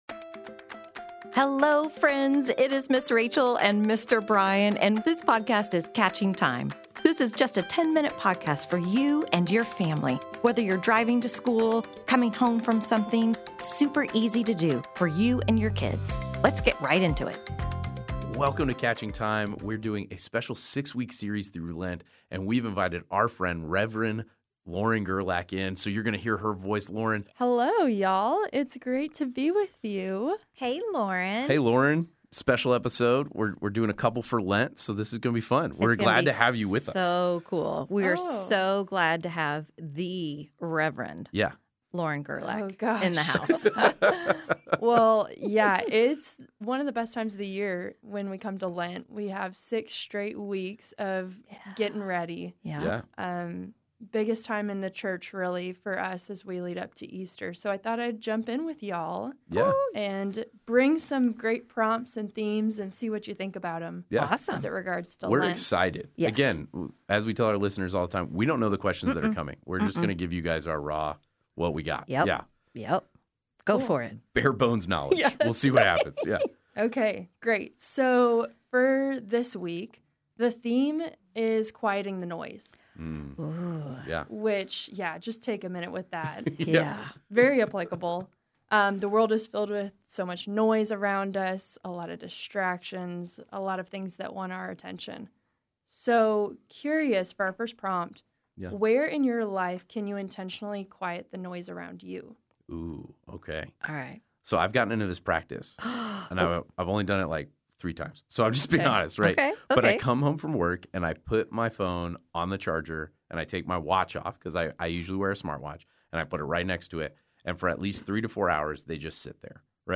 Message: "Quieting The Noise